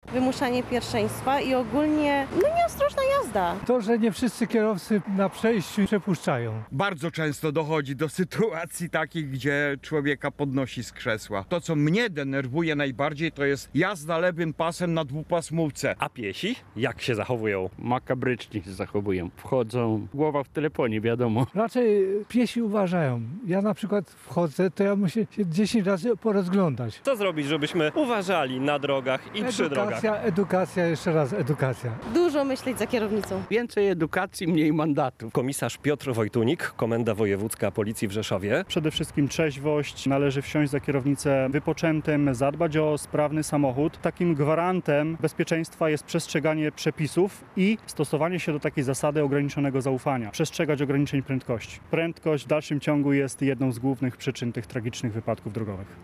Wiadomości • Święto wymyślili Francuzi, ale przyjęło się także w Polsce, a potrzeba jego istnienia jest ogromna - mówią mieszkańcy Podkarpacia.
Zdaniem mieszkańców regionu, których zapytaliśmy o uprzejmość za kierownicą, najważniejsze jest poprawne parkowanie, tak, aby zajmować jedno miejsce i nie zastawiać wyjazdów.